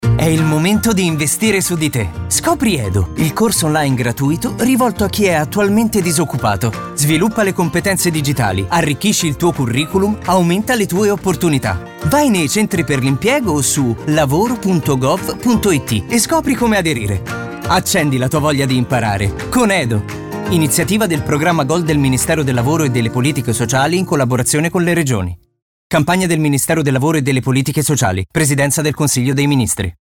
Lo spot radio
spot-edo-radio.mp3